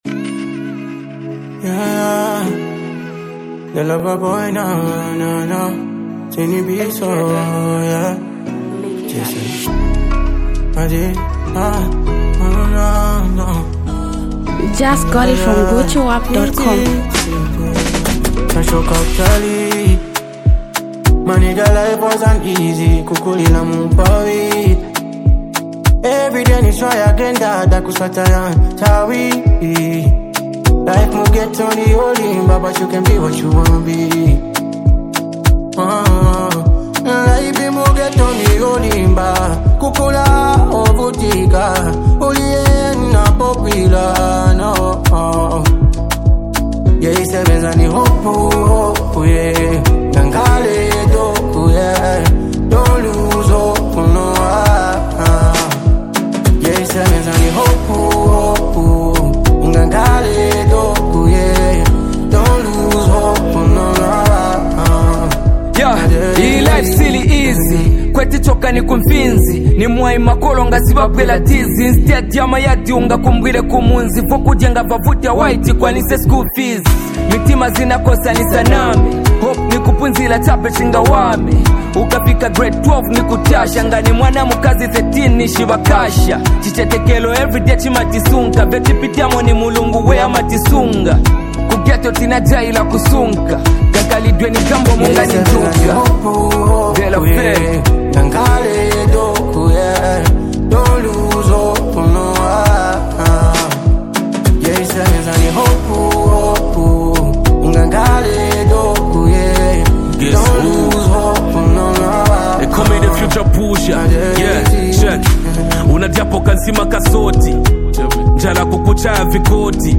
up talented singer, songwriter and rap sensation!
powerful Afro-beat song